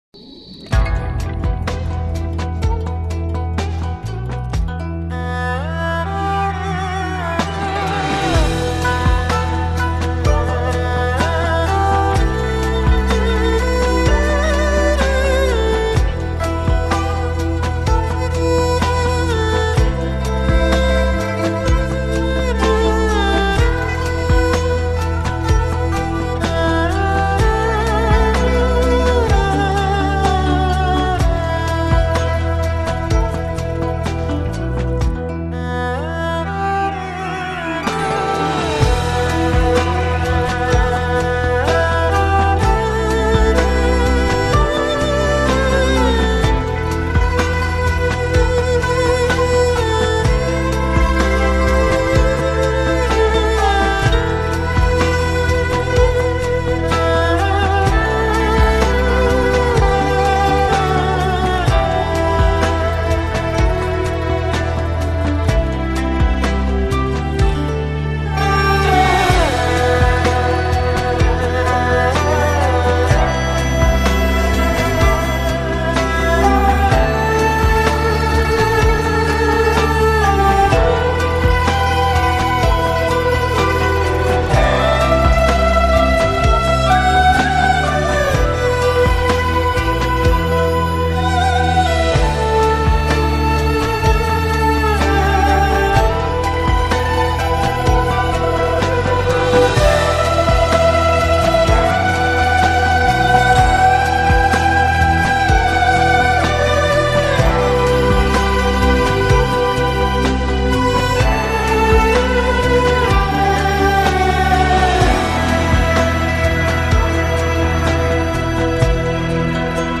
一種充滿現代氣息的聲音，如隨風潛入夜的春雨
專為汽車音響量身定做的HI-FI唱片，